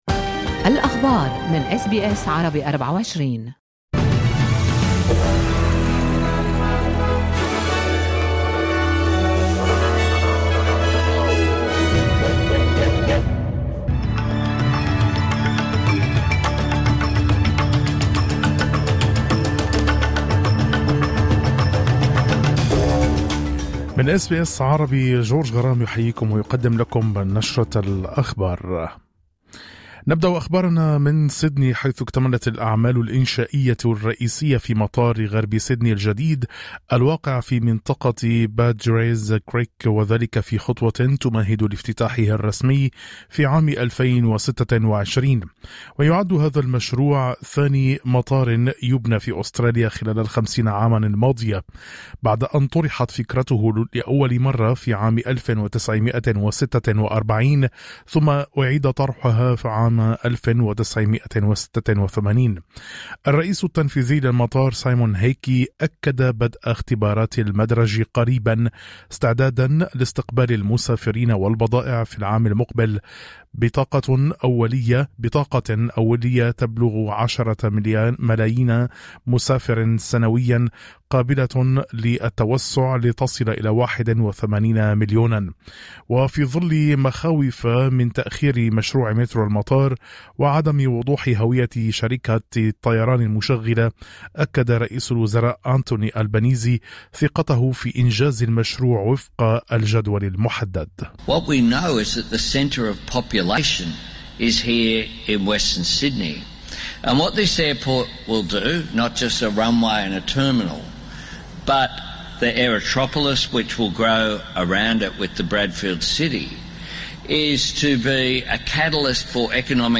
نشرة أخبار الظهيرة 11/06/2025